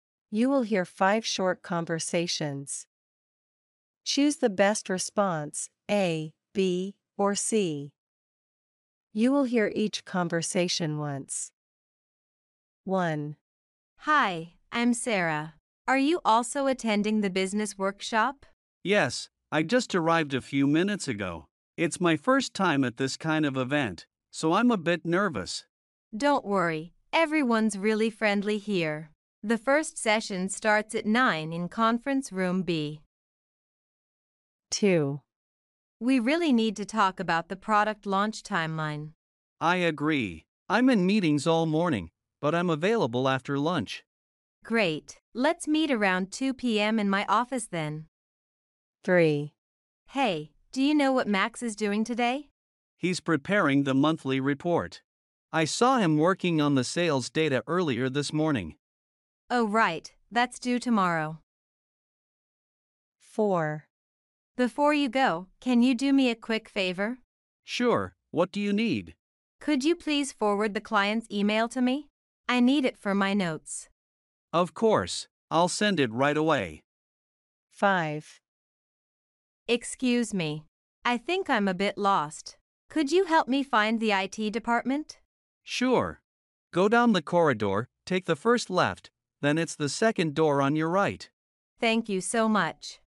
Task 1: You will hear five short conversations.